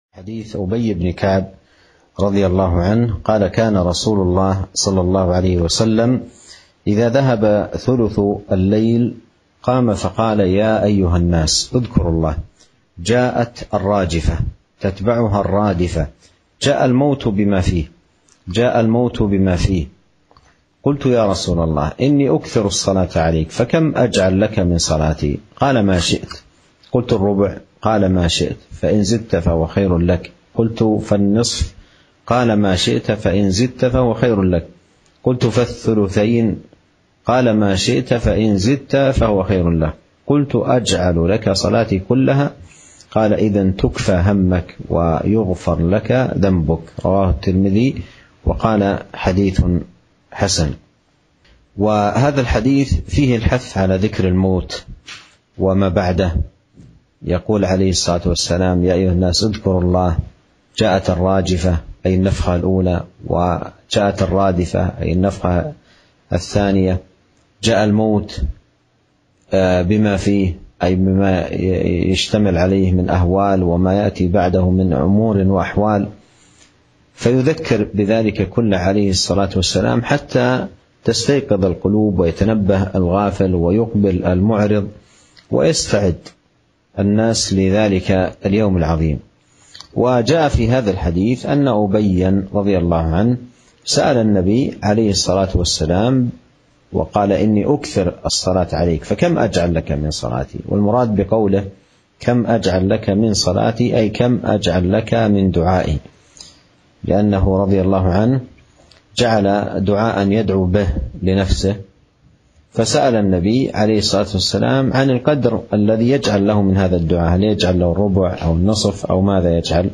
شرح حديث جاءت الراجفة تتبعها الرادفة جاء الموت بما فيه